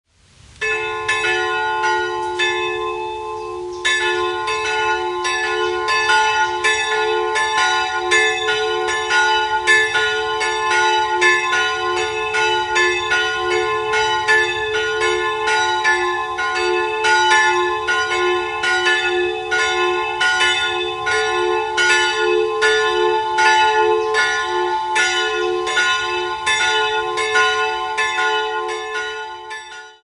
Jahrhundert. 2-stimmiges Kleine-Terz-Geläute: fis''-a'' Glocke 1 fis'' 100 kg 54 cm 15.